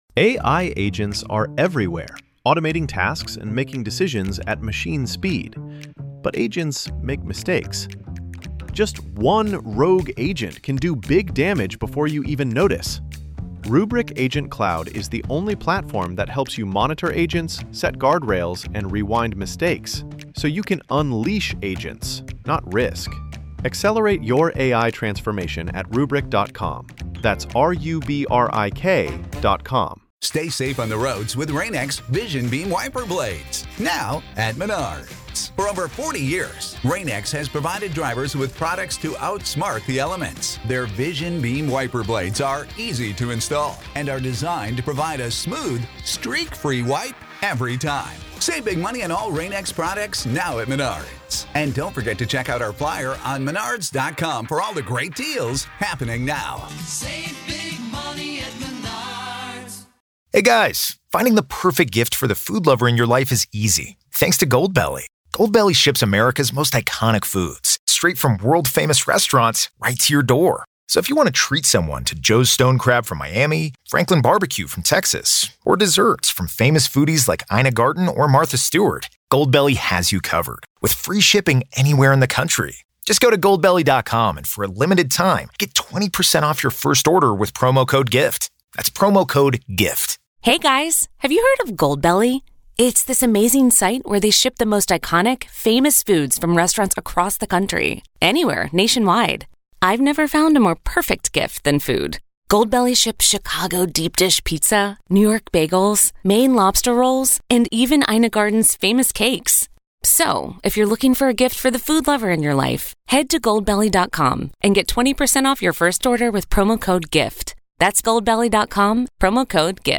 In this segment, the hosts dive into architecture, energy, and murder — specifically the story of Frank Lloyd Wright’s Wisconsin estate, Taliesin, where a handyman murdered Wright’s mistress and six others with an ax...